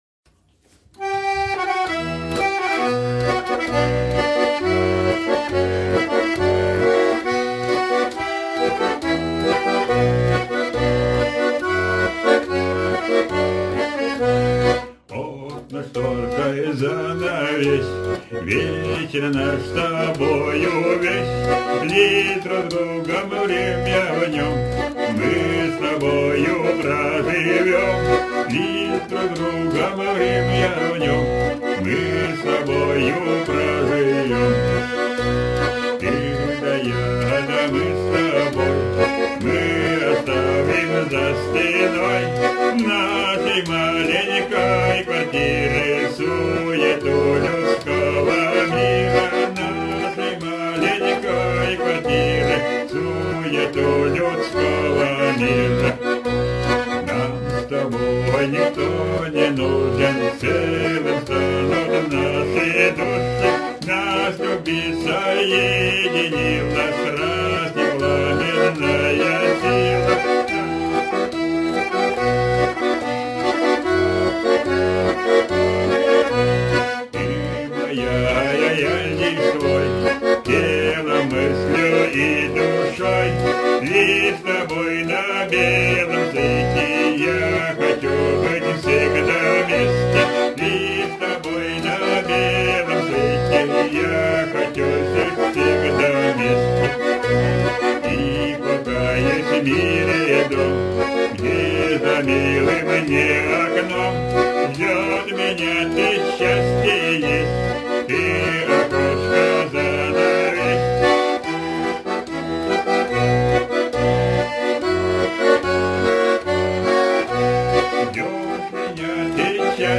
Песня Гармонь Стихи